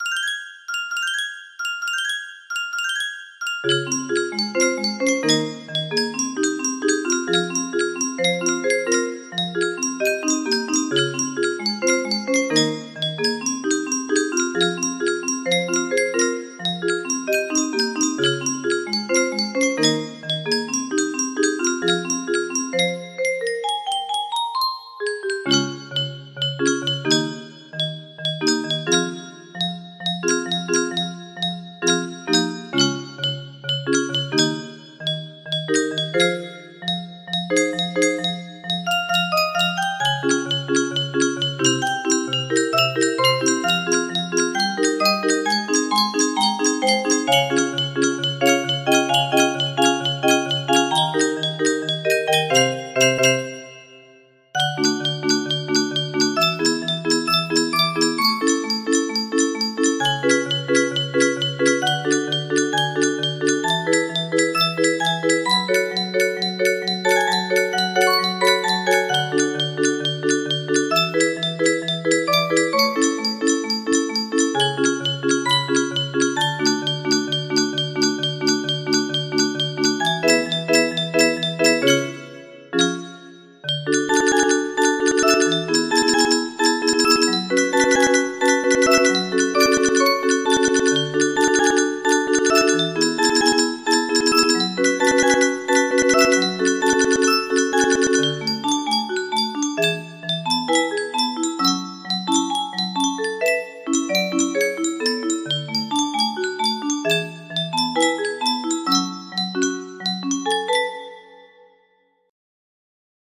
Clone of Unknown Artist - Untitled music box melody
Full range 60